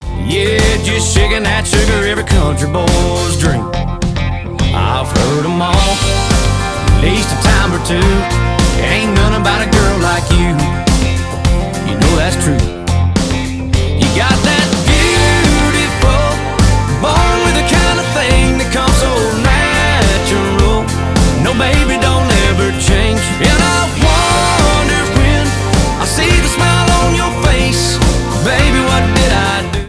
• Country